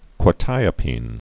(kwə-tīə-pēn)